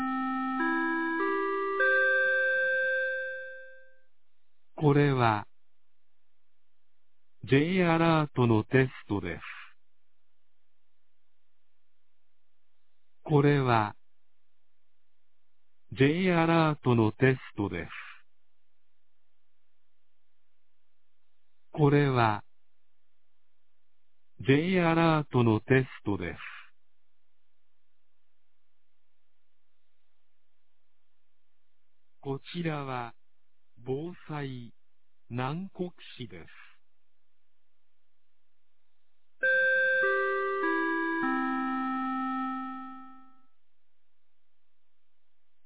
2023年11月15日 11時00分に、南国市より放送がありました。